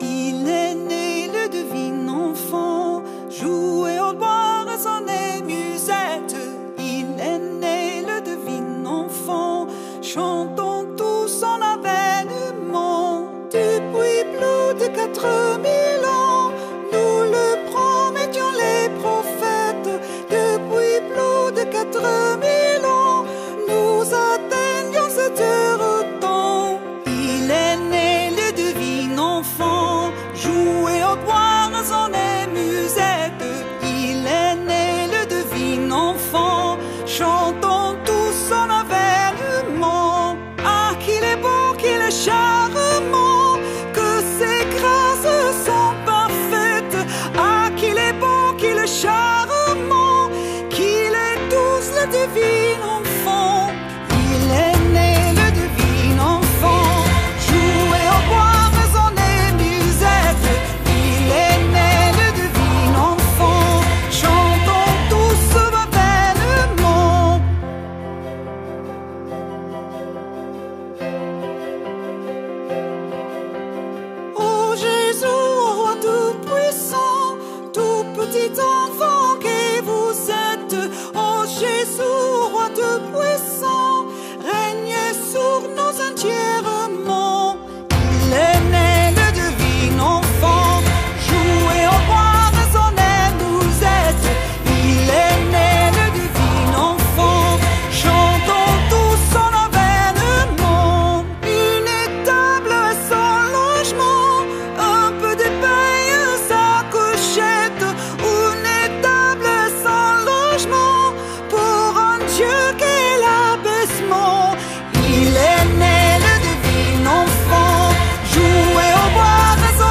Übungsmusik aus einigen YouTube Videos herausgeschnitten (mittlere Qualität) - als mp3- oder wav-File